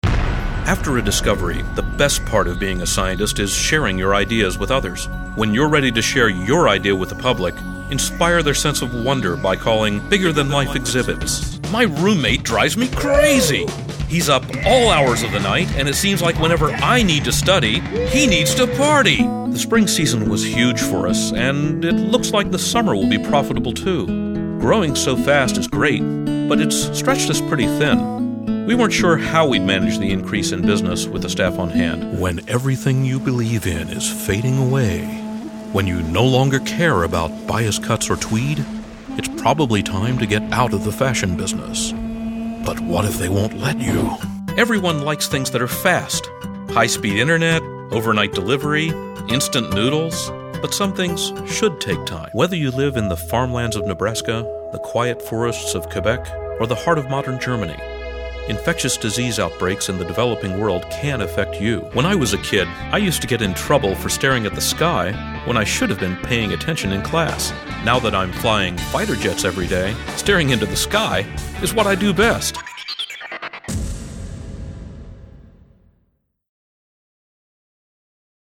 It's a quick way to show the range of your voice, and it is one of the primary tools casting agents use to decide on which voice actor to employ for a project.
Samples Commercial Demo (1:19) Commercial Demo Narrative Demo (1:56) Narrative Demo
01-commercial.mp3